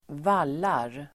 Uttal: [²v'al:ar]